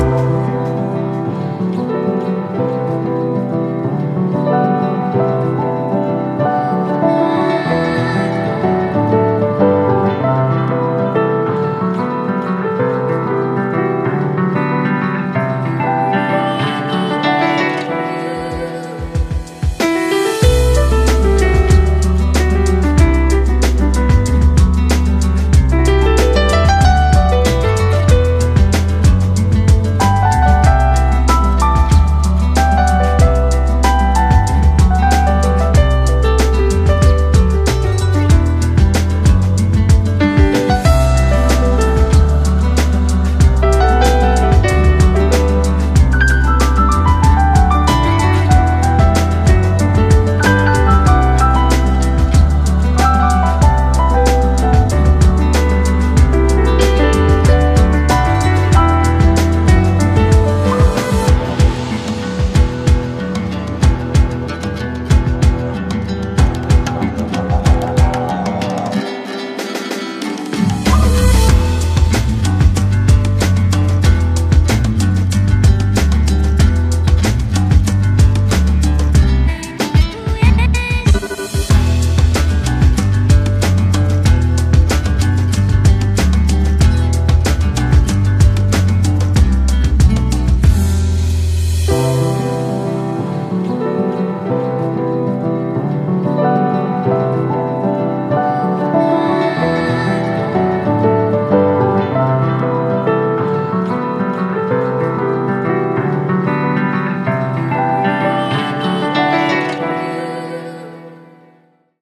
BPM94
Comments[CHILL HIP HOP]